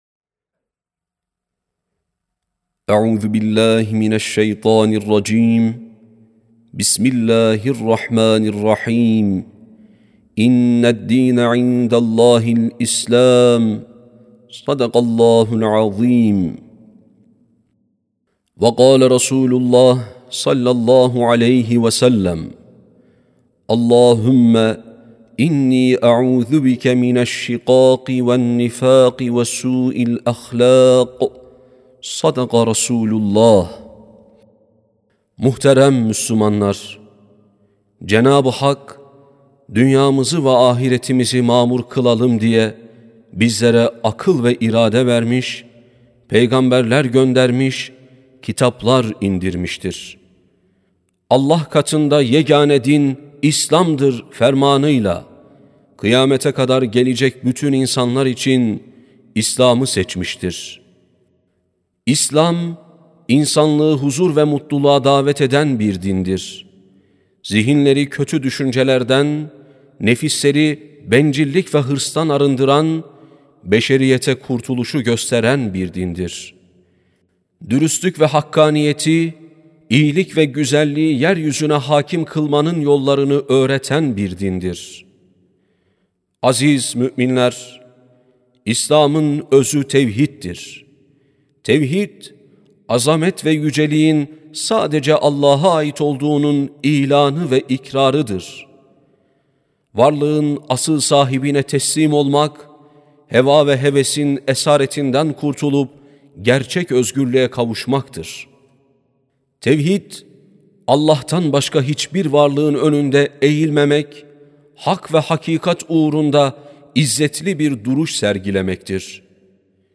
10.04.2026 Cuma Hutbesi: İslam (Sesli Hutbe, Türkçe, İngilizce, İspanyolca, İtalyanca, Rusça, Arapça, Almanca, Fransızca)
Sesli Hutbe (İslam).mp3